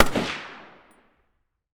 PRC RIFLE0FR.wav